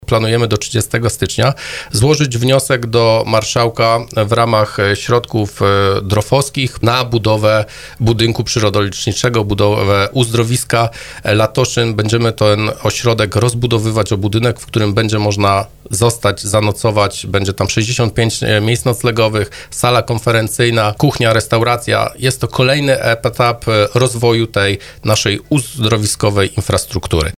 Zastępca wójta gminy Dębica Grzegorz Skrzypek przyznał na antenie Radia RDN Małopolska, że gmina musi szukać dofinansowania.